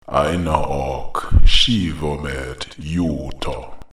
eye-naw-awk    shee-vaw-meht    yoot-taw